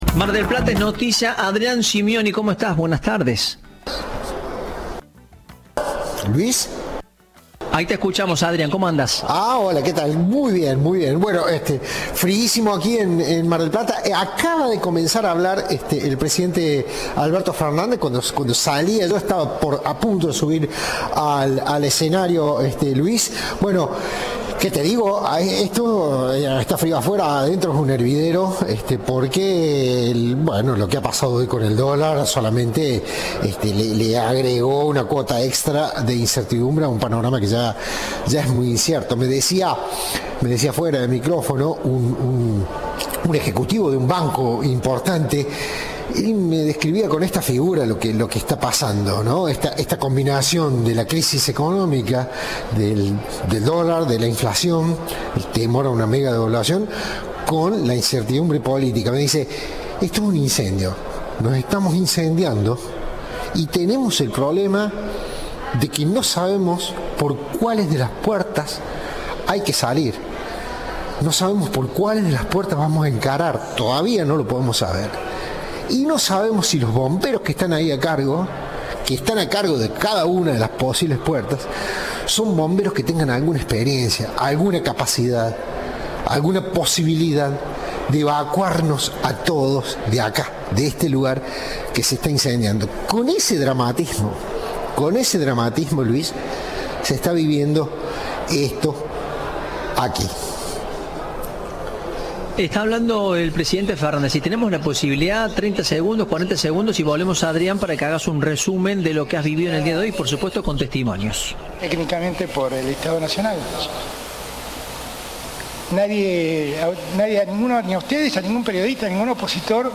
En el marco del Congreso IDEA celebrado en Mar del Plata, el presidente de la Unión Industrial Argentina (UIA), Daniel Funes de Rioja, ofreció declaraciones a Cadena 3 y medios de prensa presentes sobre la ausencia de referentes políticos como Javier Milei y Sergio Massa en el evento, además de abordar el escándalo en torno al caso del intendente de Lomas de Zamora, Martín Insaurralde.